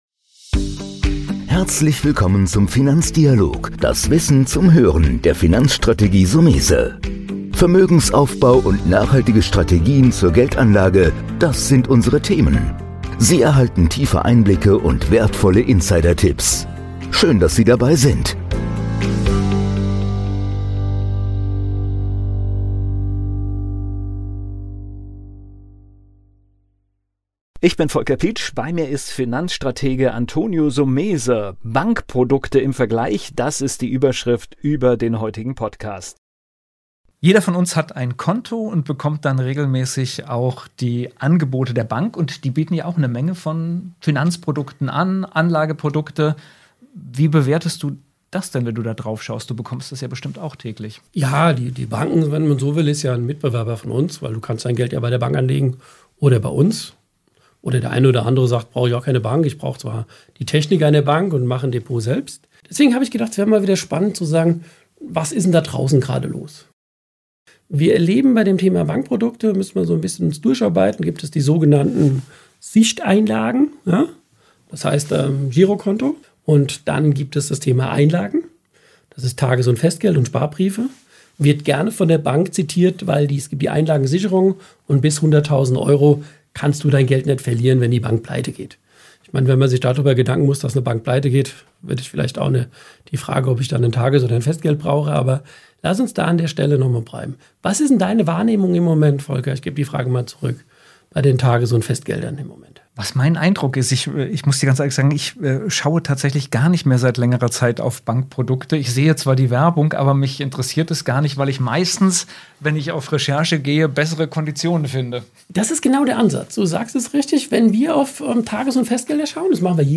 Im Dialog mit unseren Gästen öffnen wir das Fenster zu Wirtschaft, Kapitalmarkt und Finanzwelt.